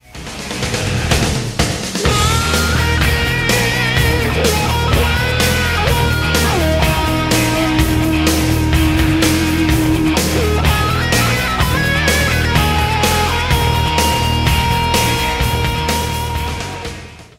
tónica mi♭